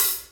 SZ HHAT 09.wav